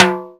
727 Timbale Hi.wav